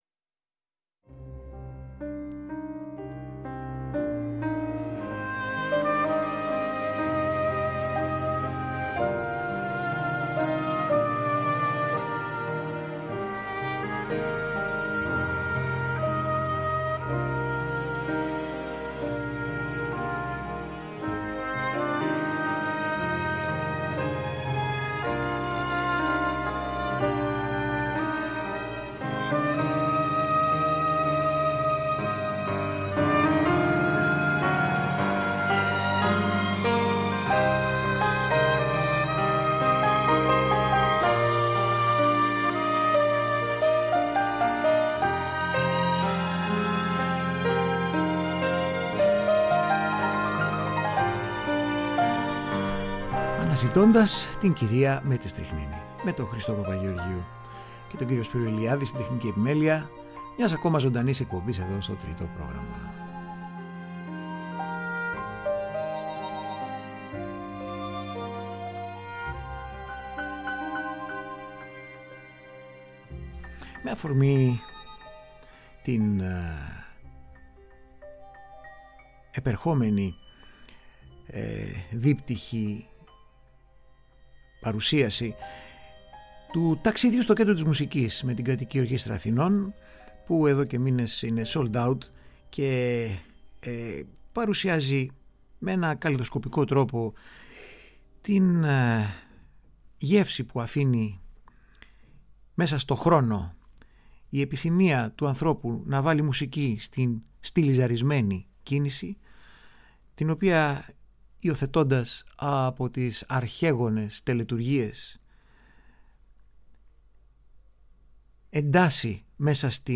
Μουσική μπαλέτου Μερικές από τις ωραιότερες και πιο αντιπροσωπευτικές μουσικές από τις απαρχές της αυλικής χορευτικής έκφρασης μέχρι τις ημέρες μας .